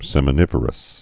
(sĕmə-nĭvər-əs)